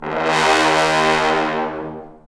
Index of /90_sSampleCDs/Roland L-CD702/VOL-2/BRS_Bs.Trombones/BRS_Bs.Bone Sect